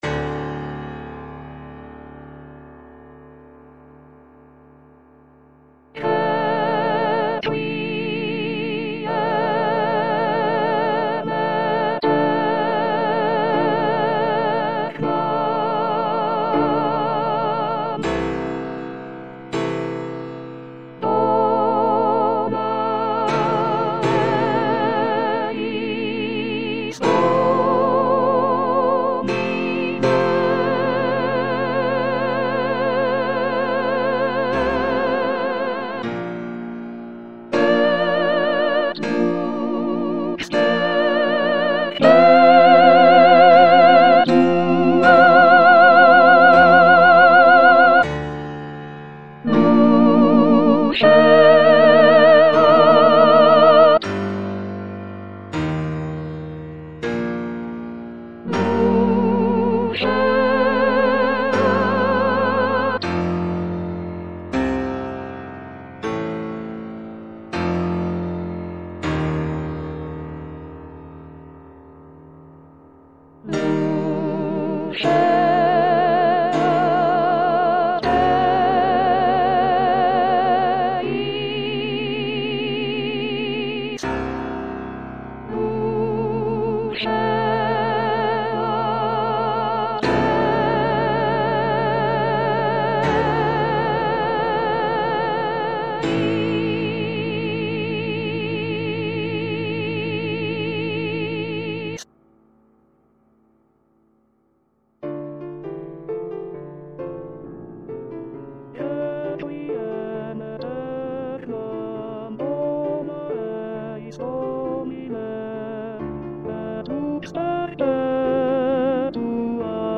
ATTENTION : ces fichiers audio comportent peu ou pas de nuances, il ne s'agit (normalement!) que des bonnes notes à la bonne place
avec la bonne durée chantées par des voix synthétiques plus ou moins agréables .